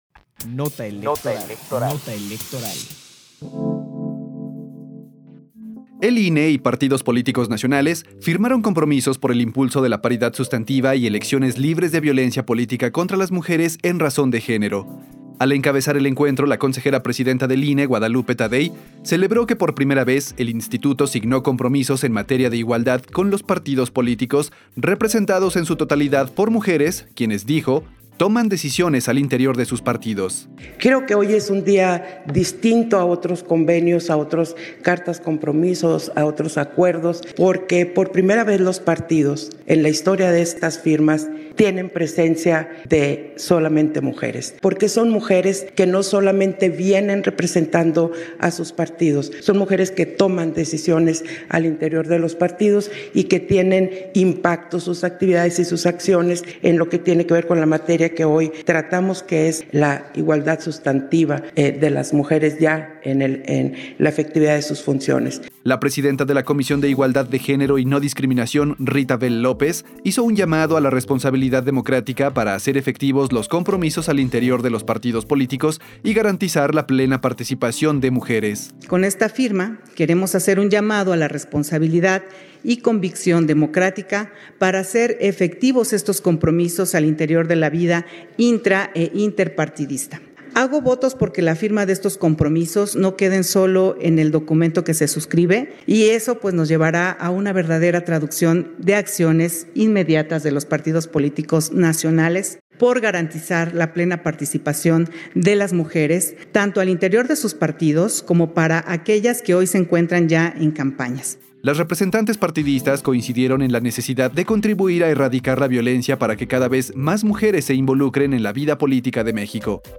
Nota de audio sobre la firma de compromisos entre el INE y Partidos Políticos Nacionales por el impulso de la paridad sustantiva, 8 de marzo de 2024